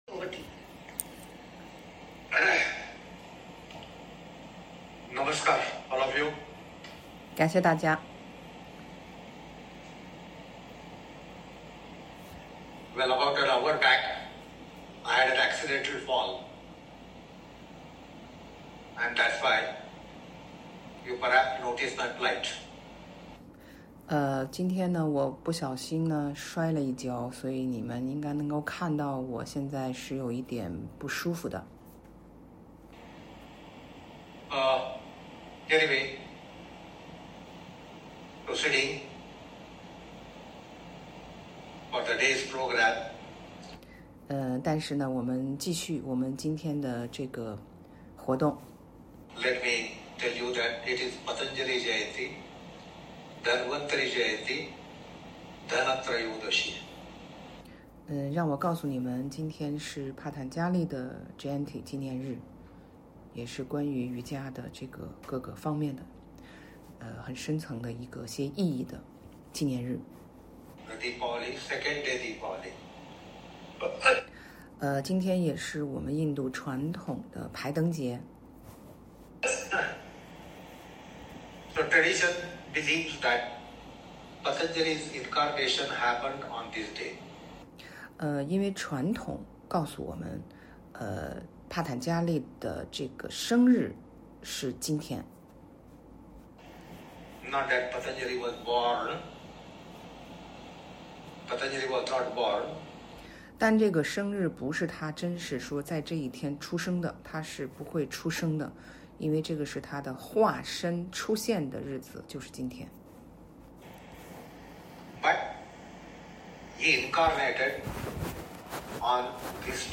因為錄製的原因（無法錄到You-tube），在此只能分享音訊資料，請見諒。